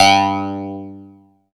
69 CLAV G2-L.wav